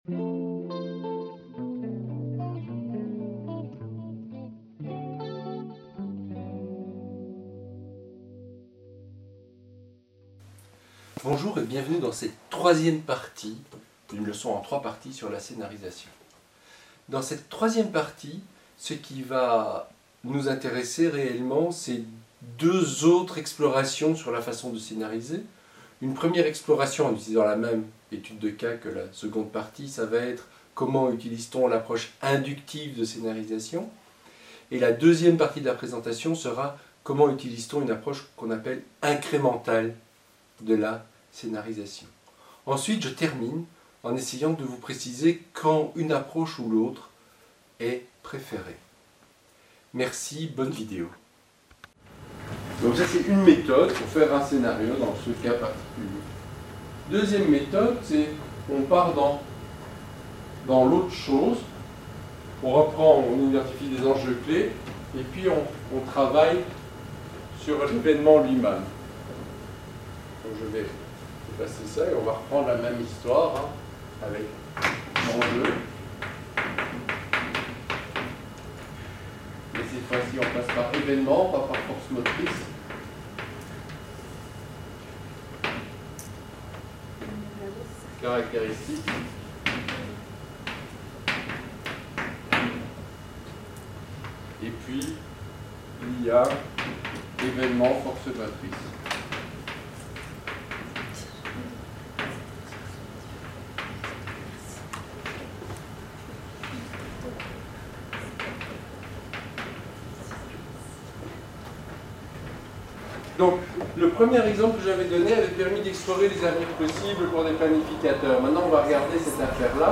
Il s'agit de la troisième partie d'une leçon en trois parties sur la scénarisation prospective dans un contexte d'adaptation côtière aux changements climatiques.